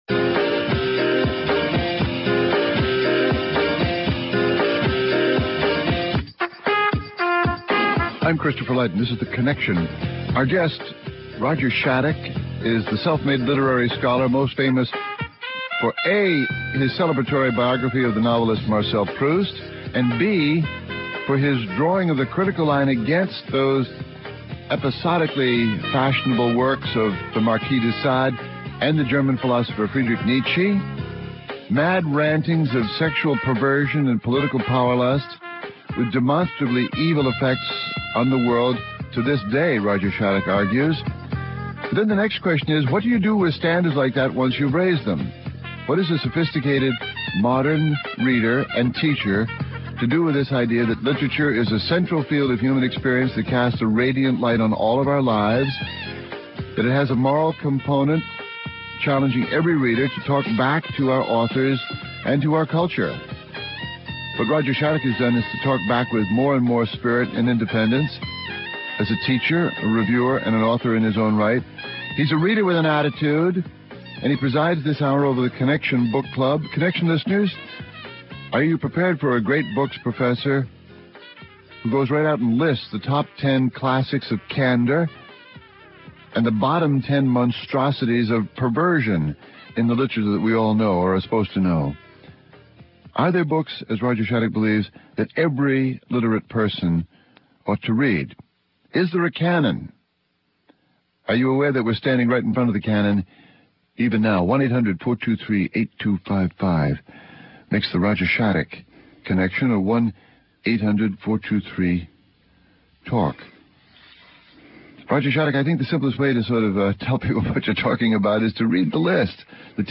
(Hosted by Christopher Lydon)
(Hosted by Christopher Lydon) Guests: Roger Shattuck, author of Candor and Perversion, a catalogue of books.